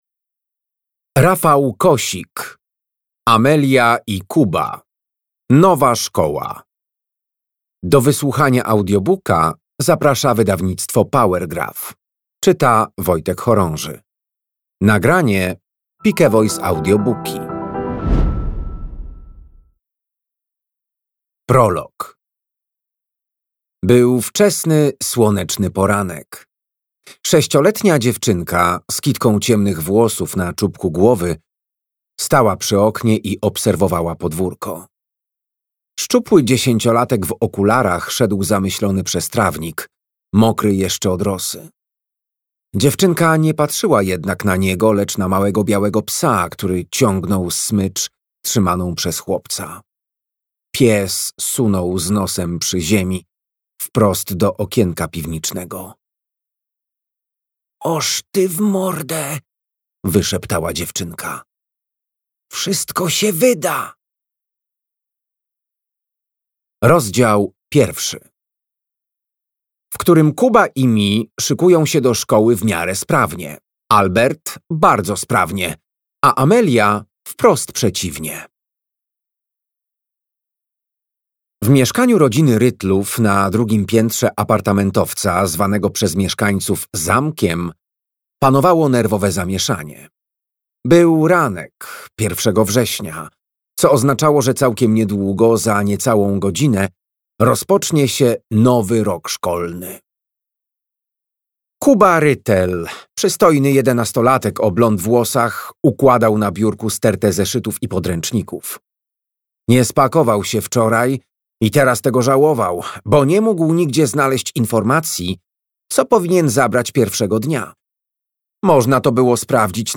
Amelia i Kuba. Nowa szkoła - Rafał Kosik - audiobook